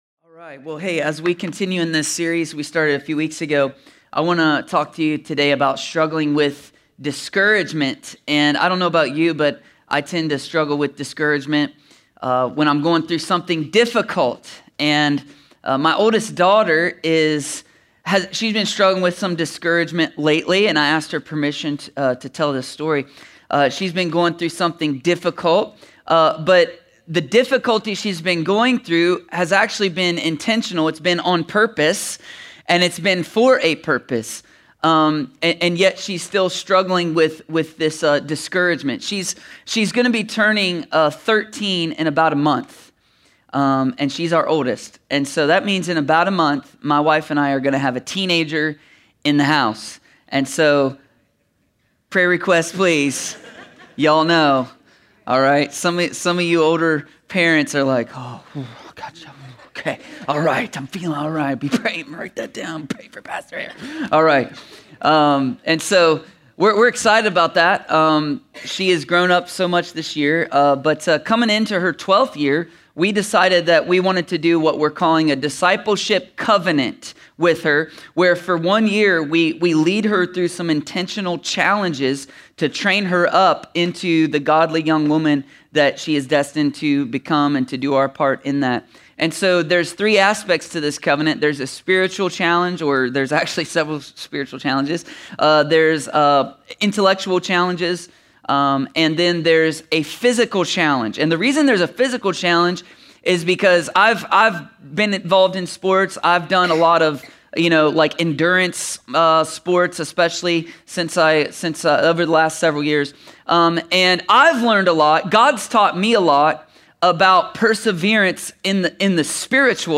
A sermon from the series “The Struggle Is Real.”…